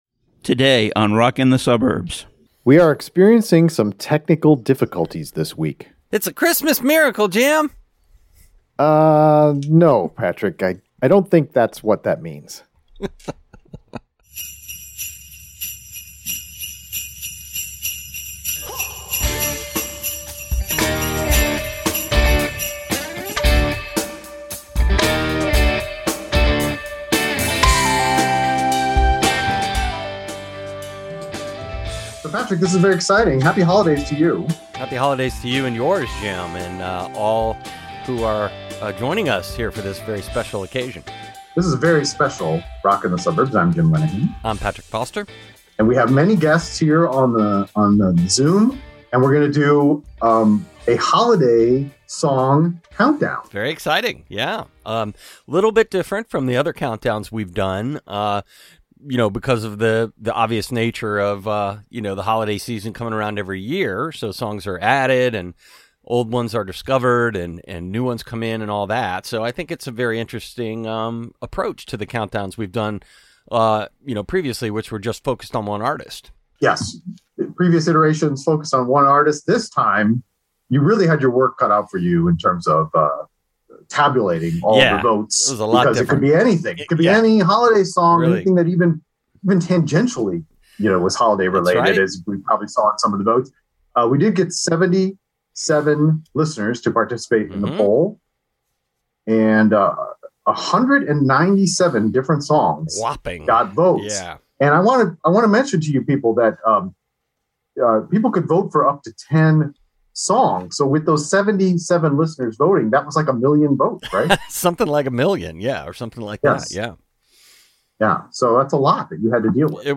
We start our holiday song countdown and reveal Nos. 10, 9 and 8 from our listener poll. The episode was recorded on Zoom with a gathering of listeners.
* Sorry about the poor audio quality; we experienced some technical difficulties.